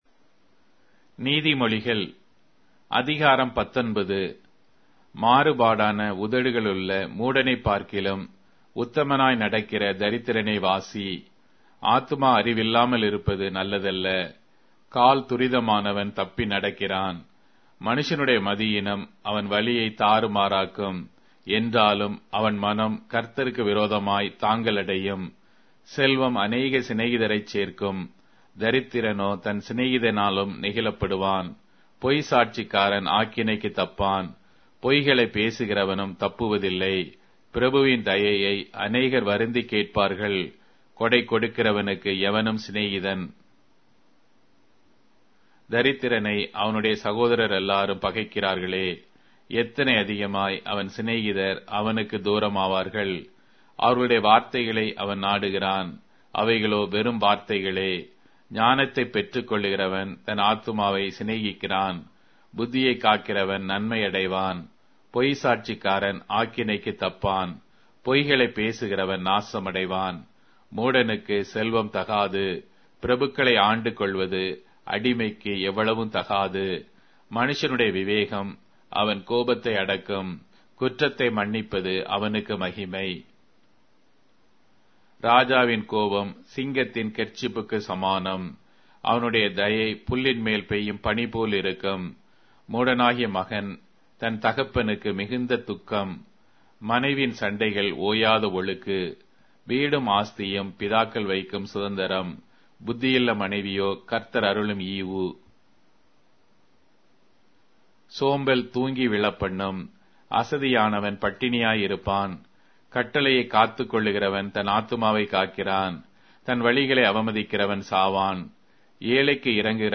Tamil Audio Bible - Proverbs 22 in Irvpa bible version